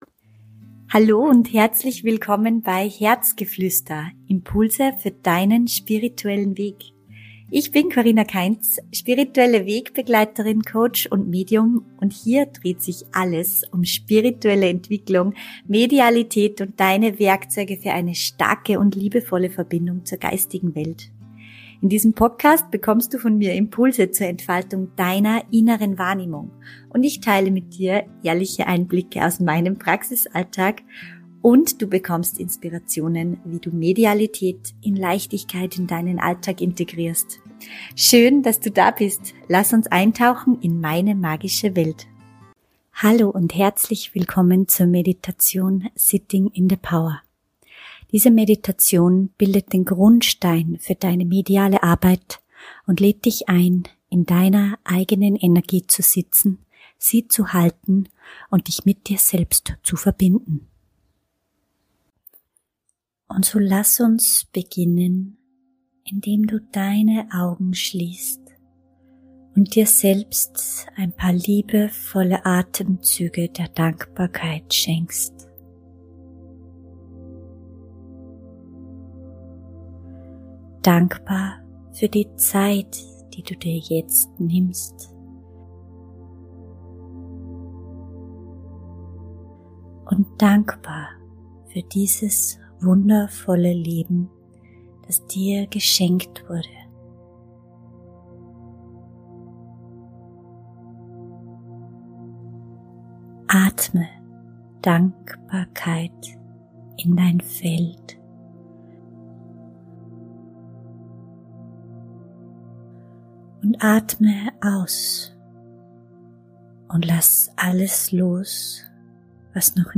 Geführte Meditation "Sitting In The Power"! Verbinde dich mit deiner Seelenkraft